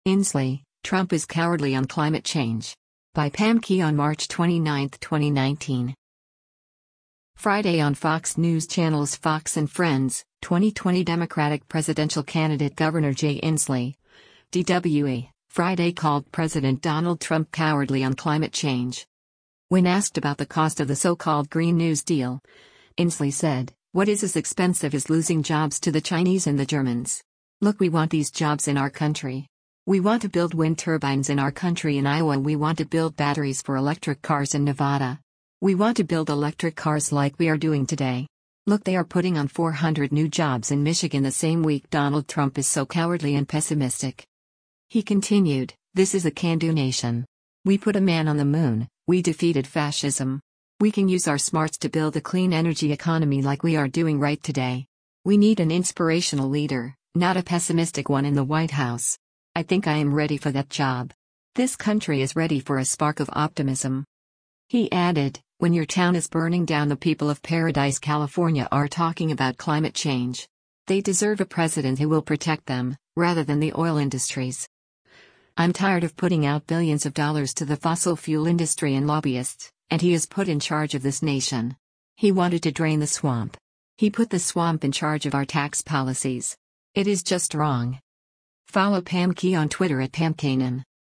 Friday on Fox News Channel’s “Fox & Friends,” 2020 Democratic presidential candidate Gov. Jay Inslee (D-WA) Friday called President Donald Trump “cowardly” on climate change.